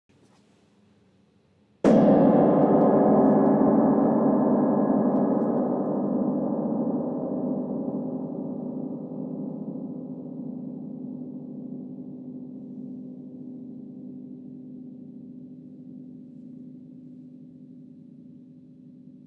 grand_1coup_faible.wav